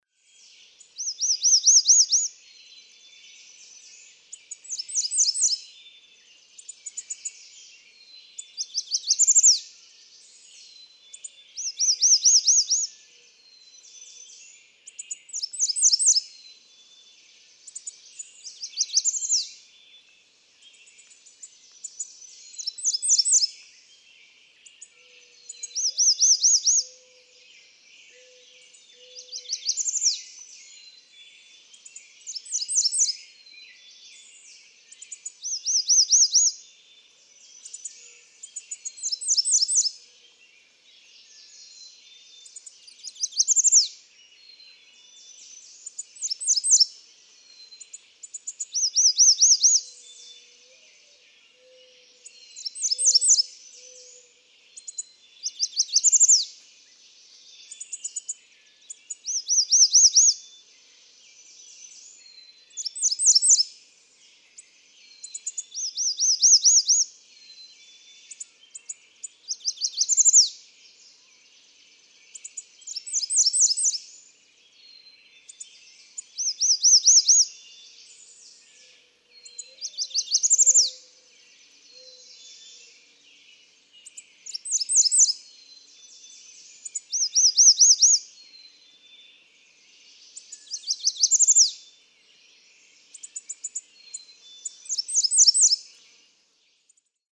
American redstart
A second male with unique, identifiable dawn songs in the 2014 neighborhood.
Cricket Hill, Conway, Massachusetts.
562_American_Redstart.mp3